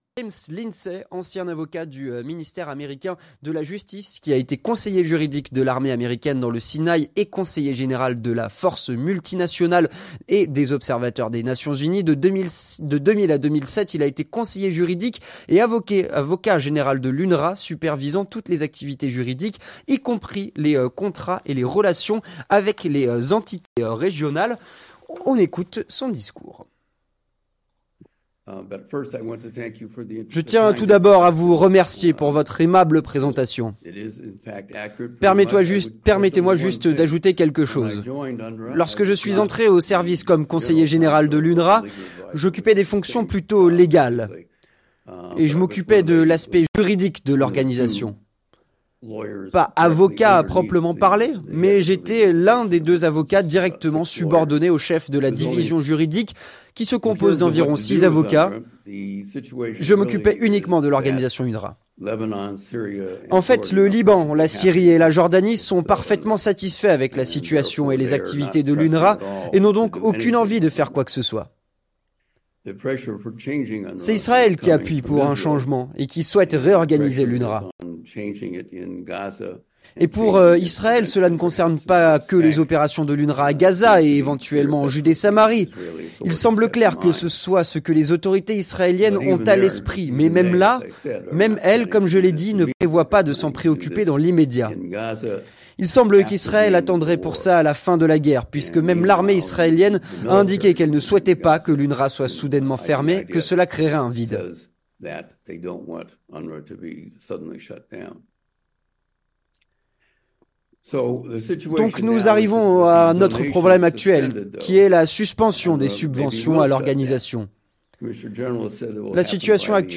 Conférence autour de l'UNRWA (23/02/24)